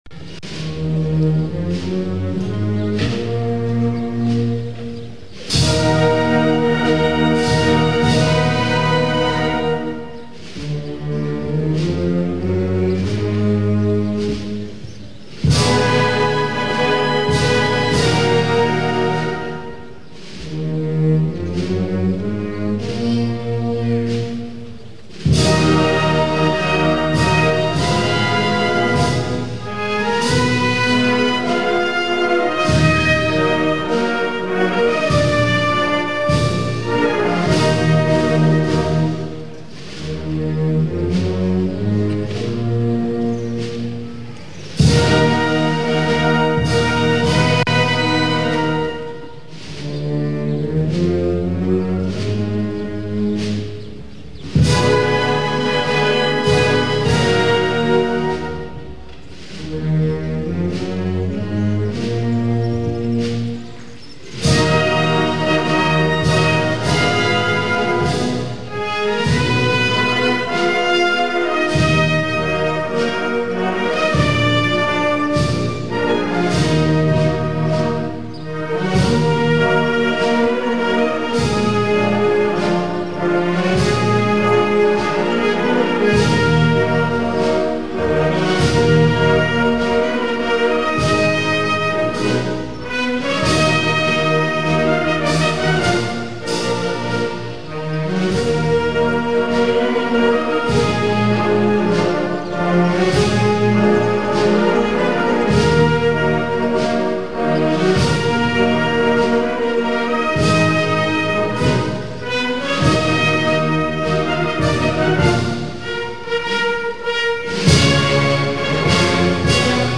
MARCHAS DE LAS PROCESIONES DE CREVILLENT